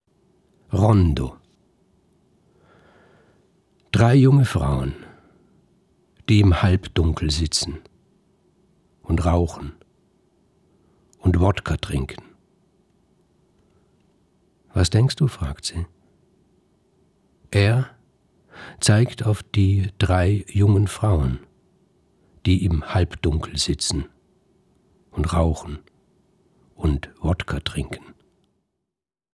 Gelesen von Michael Köhlmeier.